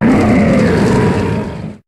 Cri de Félinferno dans Pokémon HOME.